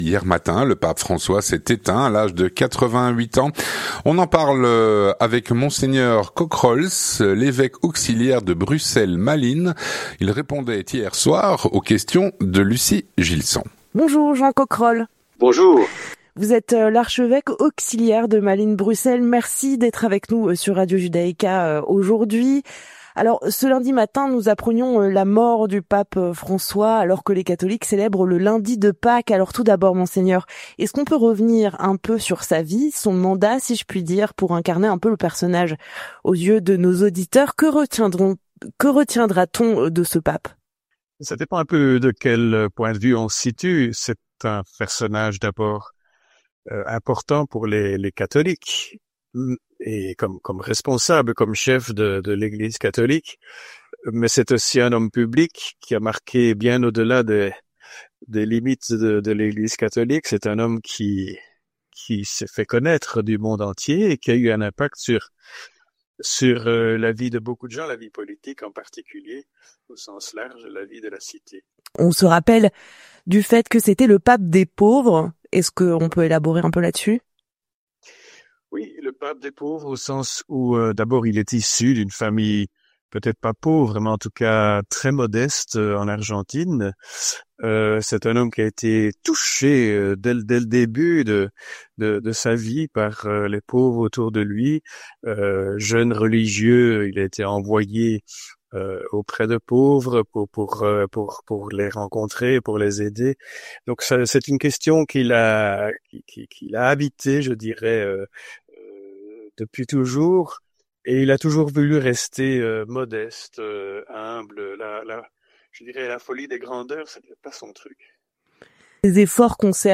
L'entretien du 18H - Ce lundi matin, le Pape François s’est éteint à l’âge de 88 ans.
On en parle avec Mgr Kockerols, Archevêque auxiliaire de Bruxelles-Malines.